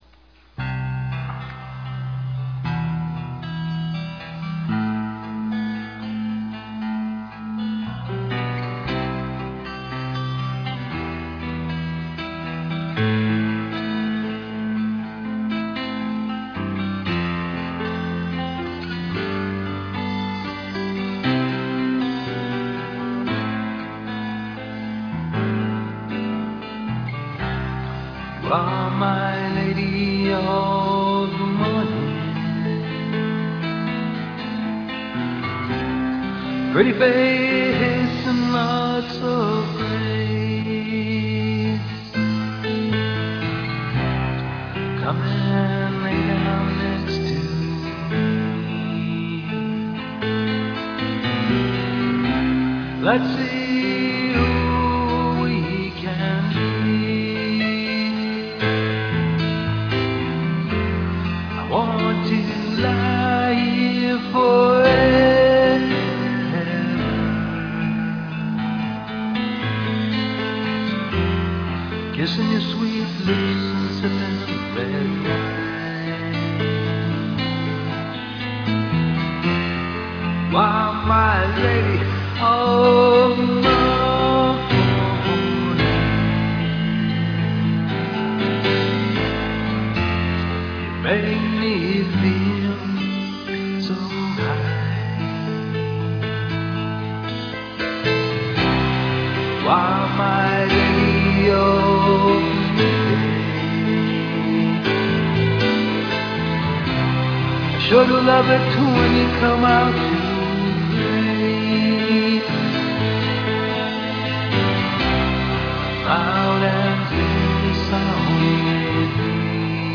The soundtrax for Lady of the Morning were recorded using an eight track Tascam portable recorder,
Roland sampler, and a Yamah midi keyboard
.   The only live portions in the recording are my voice and my guitar.
This recording is an example of what modern technology has brought to music.